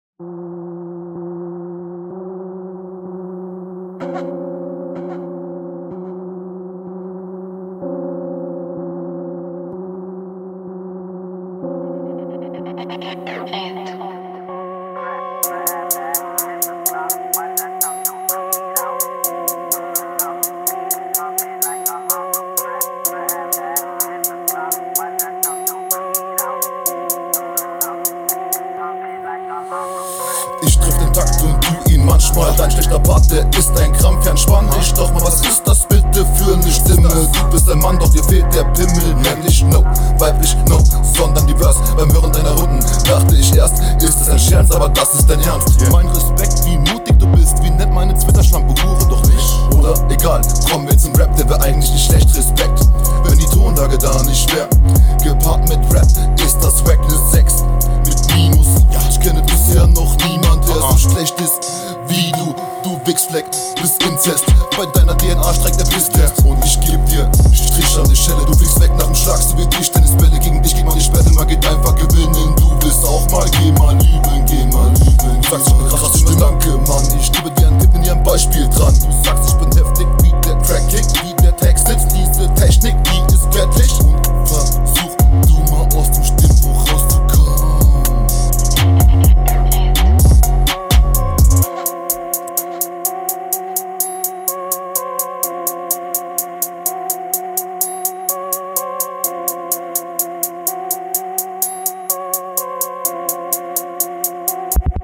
Flow: finde ich dich hier angenehmer und leicht vorne flow sowie stimmeimsatz Text: der erste …
Flow: Sehe dich hier auf dem Beat eher hinten, die Hinrunde packt mich mehr Text: …